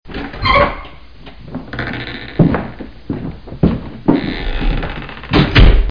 SFX推门声下载音效下载
SFX音效